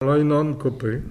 Localisation Xanton-Chassenon
Catégorie Locution